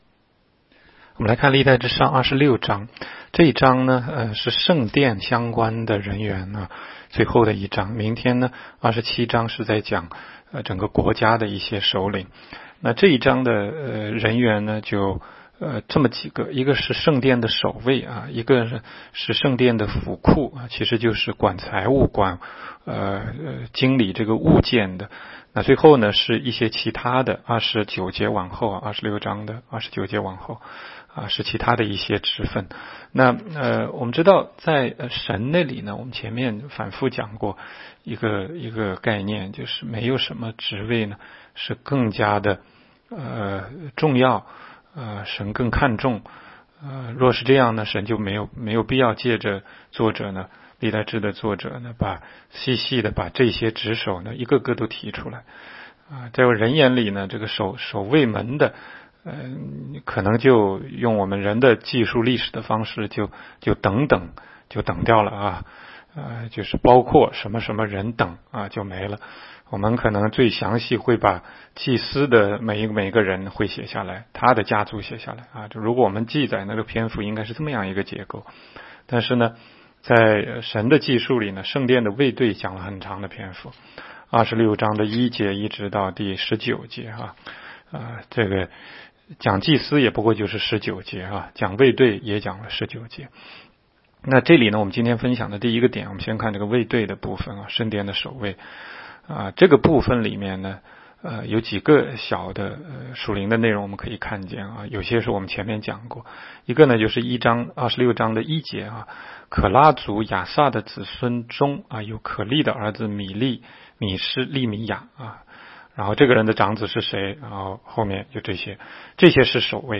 16街讲道录音 - 每日读经-《历代志上》26章